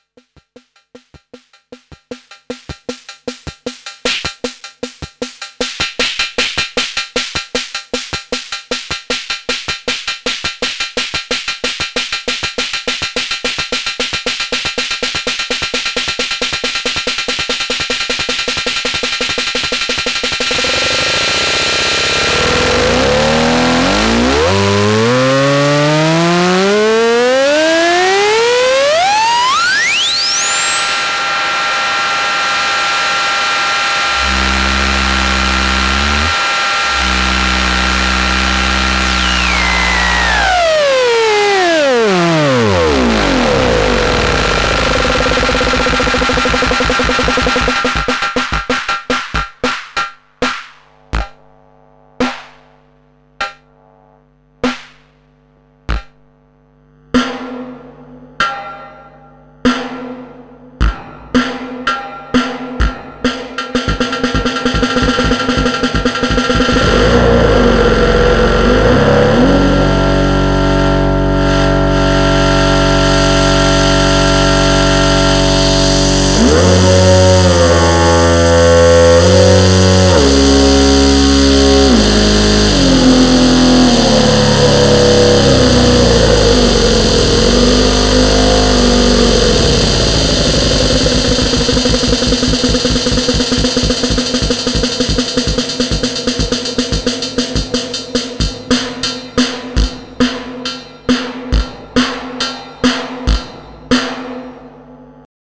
I clocked a sequential switch triggering drum modules so fast that I guess this is kind of what the kids call extratone